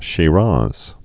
(shē-räz)